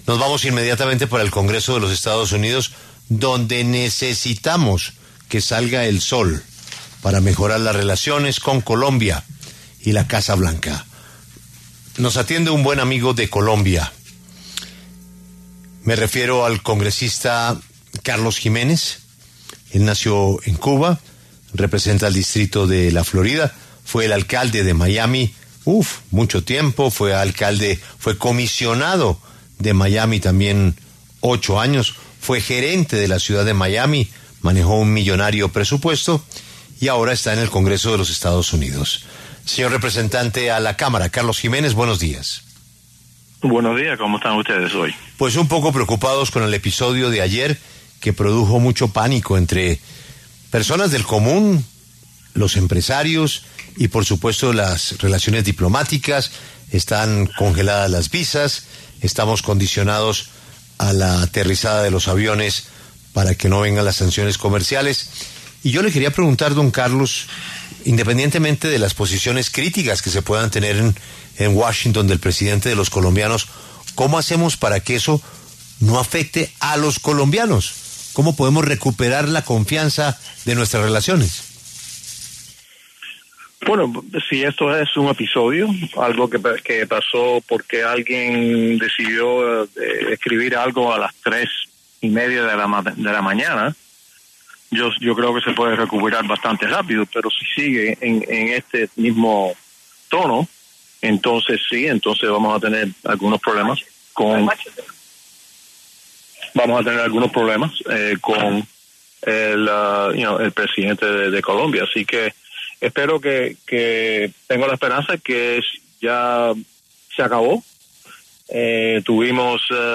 Carlos Giménez, representante del Partido Republicano, habló con La W, con Julio Sánchez Cristo, a propósito de la tensión que se desató entre Estados Unidos y Colombia luego de un intercambio de mensajes entre los presidentes Donald Trump y Gustavo Petro.